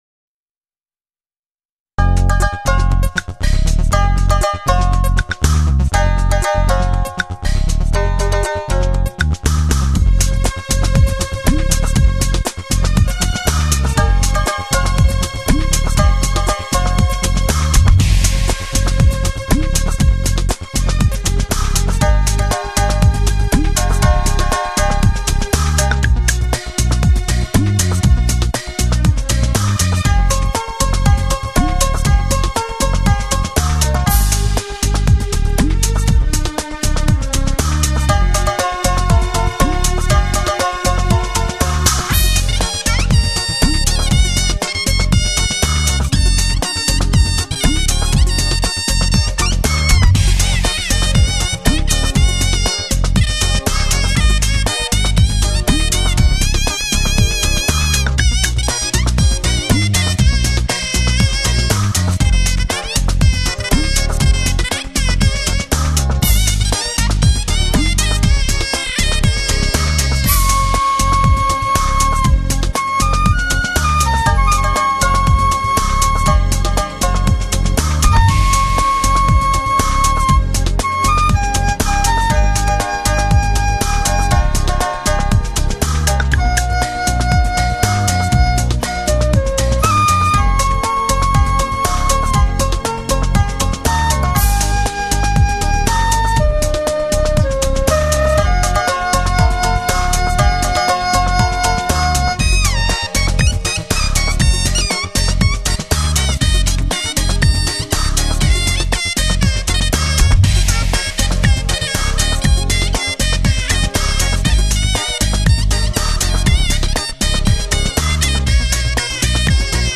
横古的音乐旋律，跨今的现代节奏；
柔美迷人的葫芦丝，撩拨着一位远古才女的心灵与丰采；
会说话传情的把乌，欢情地吟吹出她心中的爱恋和等待；
哀怨沉寂的二胡，也泣诉了悲剧时代里忧愁感伤的情怀。